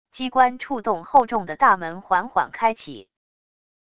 机关触动厚重的大门缓缓开启wav音频